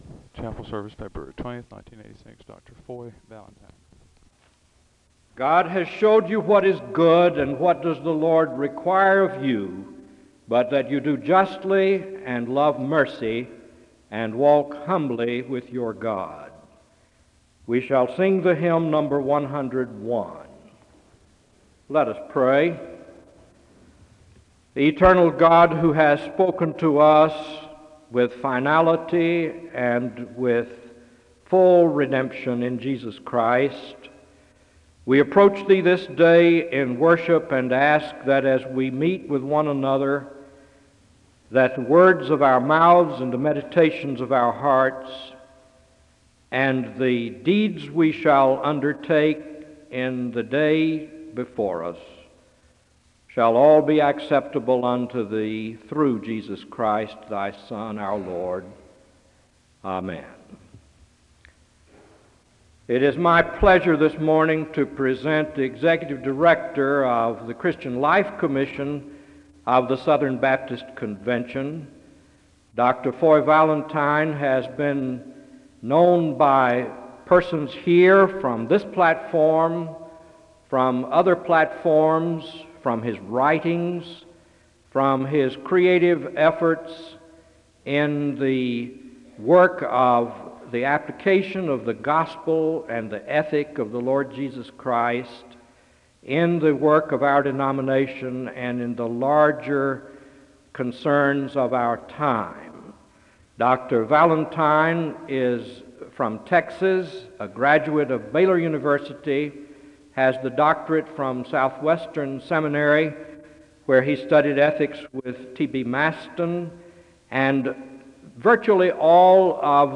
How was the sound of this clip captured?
SEBTS Chapel The service begins with a Scripture reading from Micah 6:8 and a prayer (0:00-1:01).